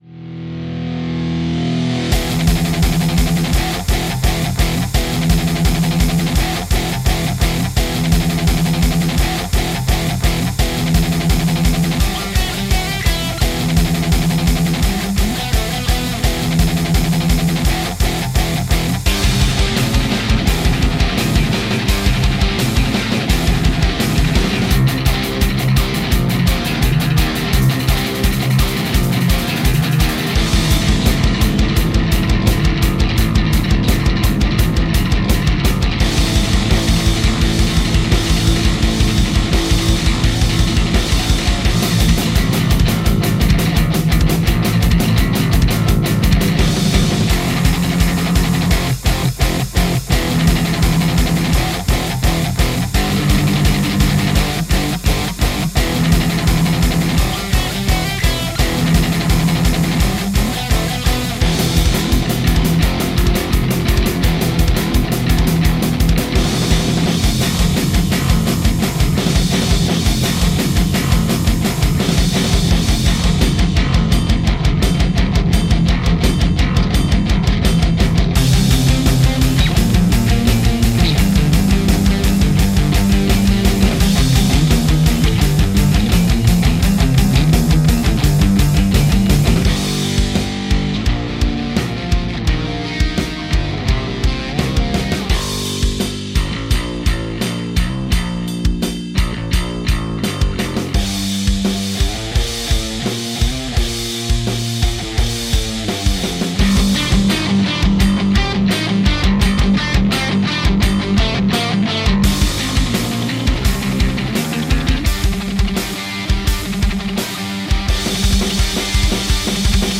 Music / Rock